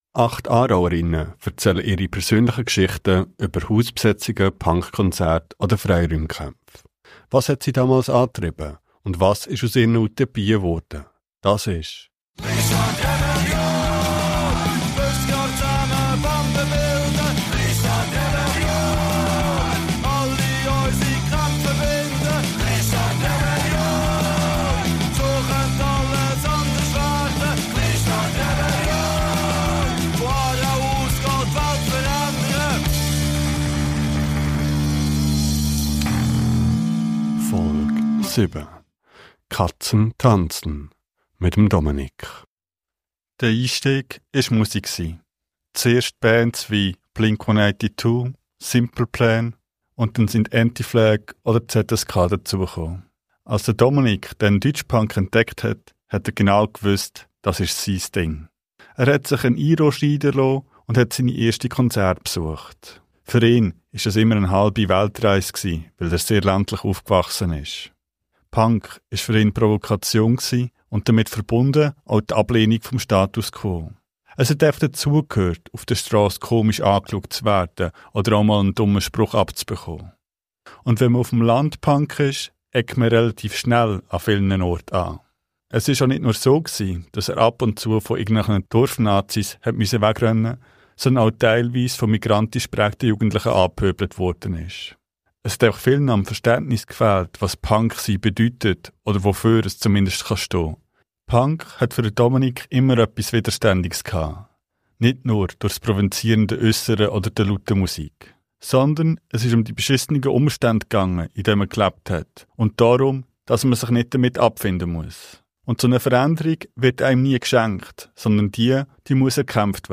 Der Podcast zum Buch: Acht Aarauer*innen erzählen ihre persönlichen Geschichten aus den letzten 50 Jahren von Hausbesetzungen, Zeitungsprojekten, Polizeirepression, Punkkonzerten, Infoläden und Demonstrationen sowie von Wünschen, Ängsten, Hoffnungen, Frustration und Idealismus.